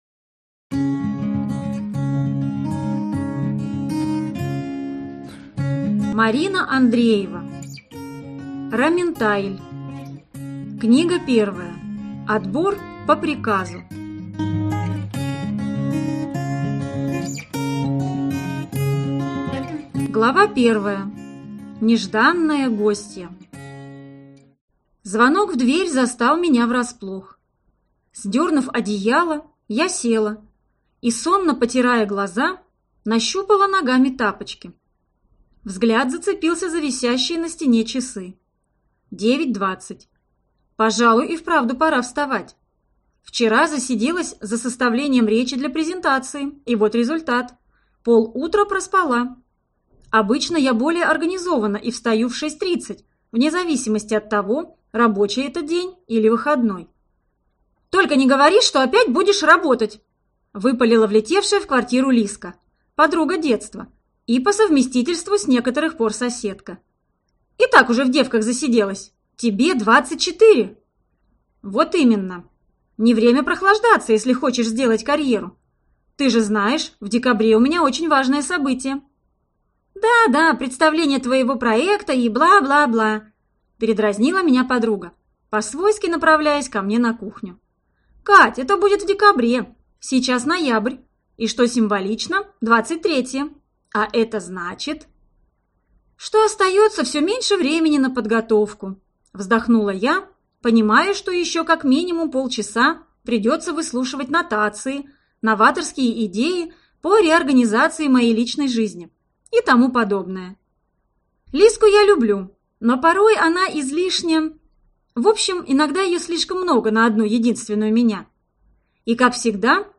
Аудиокнига Отбор по приказу - купить, скачать и слушать онлайн | КнигоПоиск